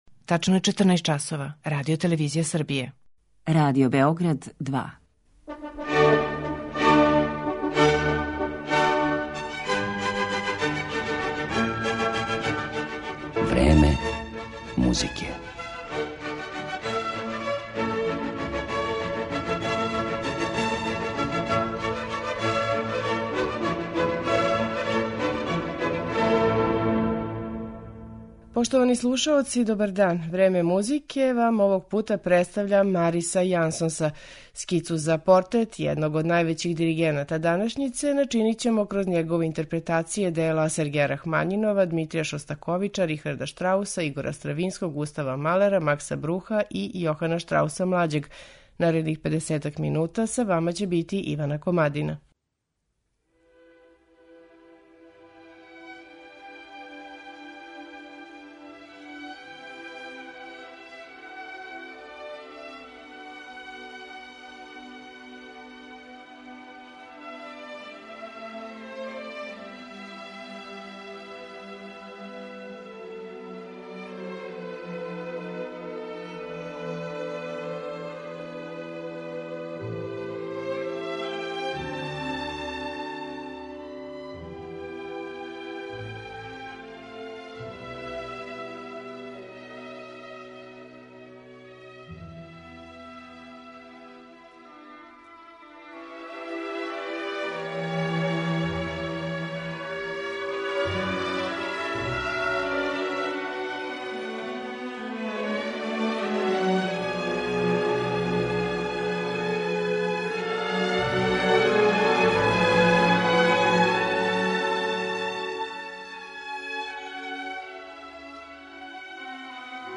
диригент